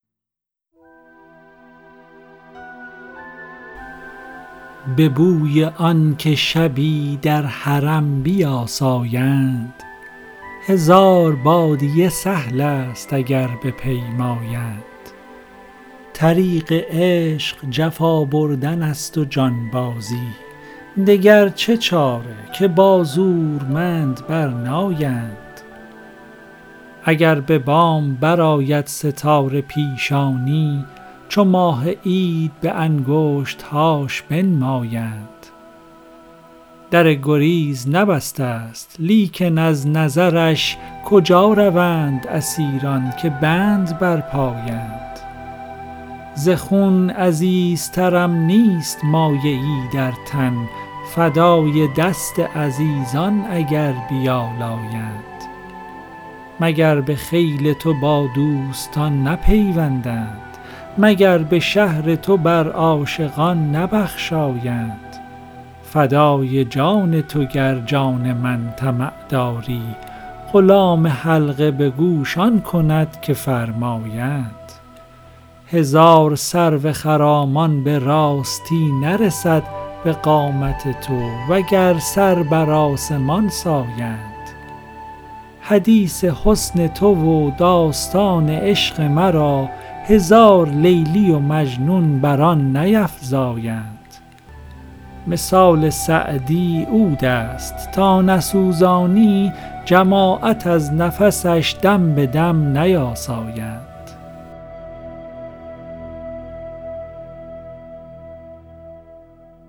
سعدی دیوان اشعار » غزلیات غزل ۲۵۳ به خوانش